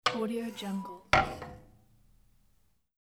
دانلود افکت صدای بسته شدن درب فلزی کوره ای
• صدای واقعی و باکیفیت: با ظرافت کامل و با بهترین تجهیزات ضبط شده، یه صدای واقعی و طبیعی از درب فلزی بهت میده.
Sample rate 16-Bit Stereo, 44.1 kHz
Looped No